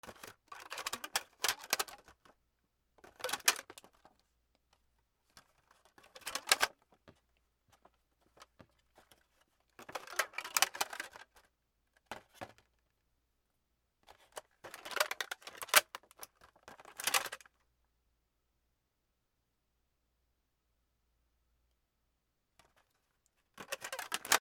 プラケースに輪ゴムをかける